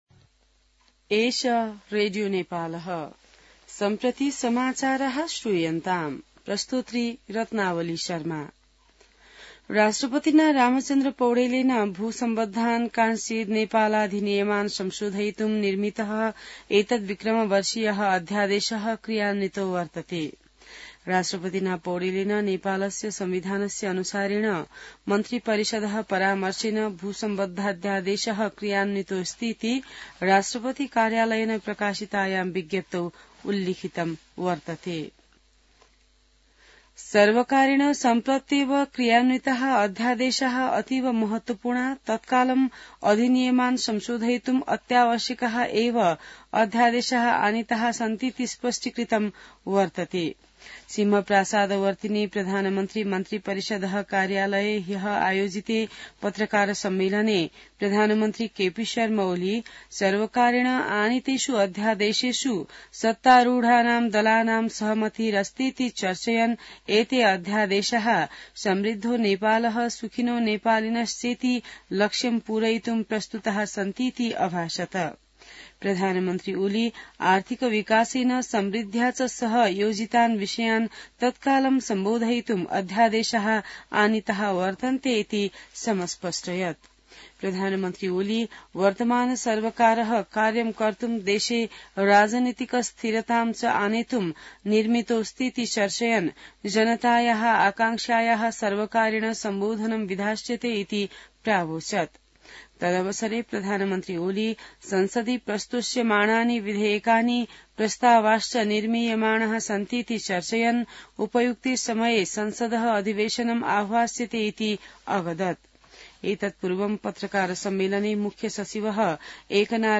संस्कृत समाचार : ४ माघ , २०८१